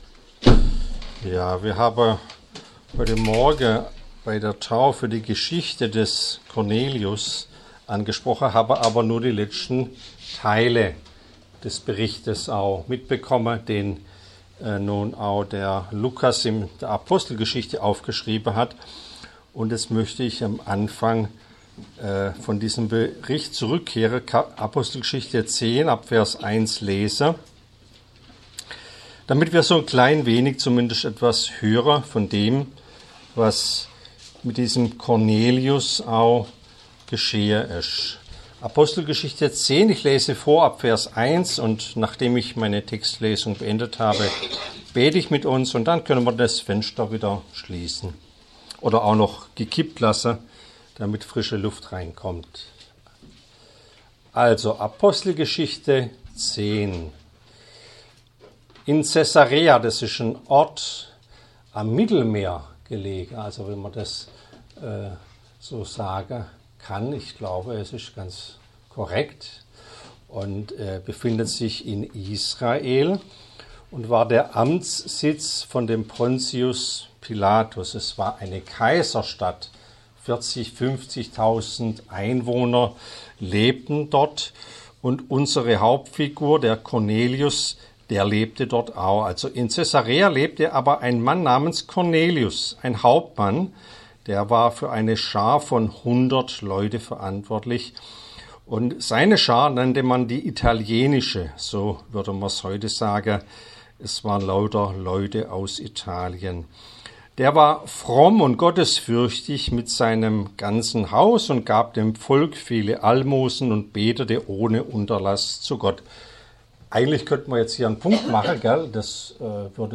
Apg-10-Taufpredigt.mp3